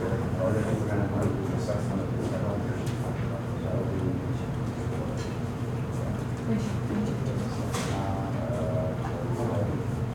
office.ogg